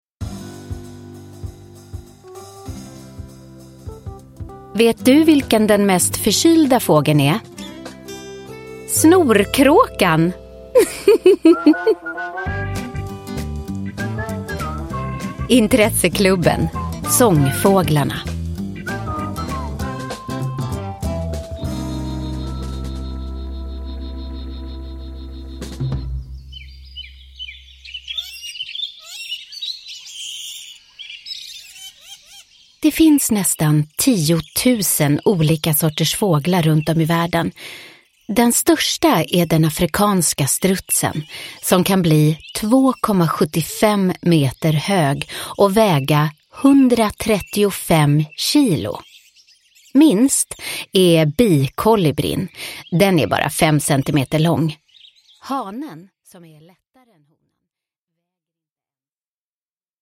Sångfåglarna och andra kraxare – Ljudbok – Laddas ner